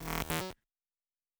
pgs/Assets/Audio/Sci-Fi Sounds/Electric/Glitch 1_03.wav at master
Glitch 1_03.wav